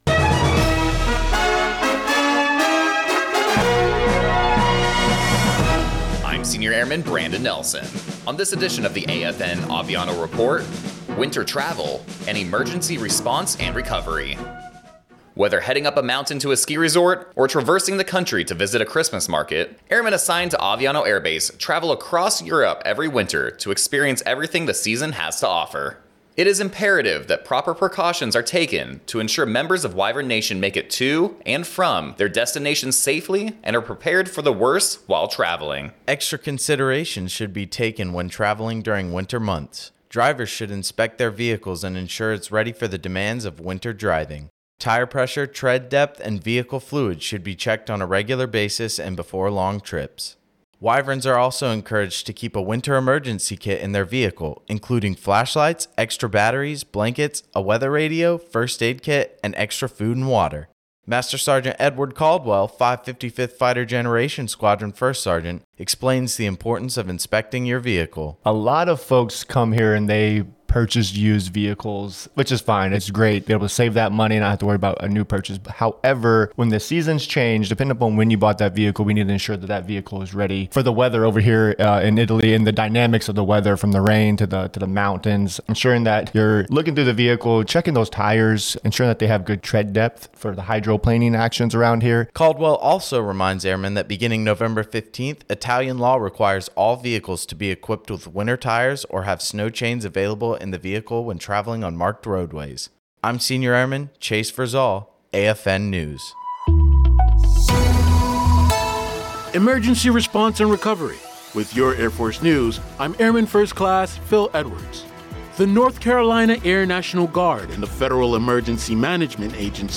American Forces Network (AFN) Aviano radio news reports on winter travel safety and the precautions families should take before driving this winter.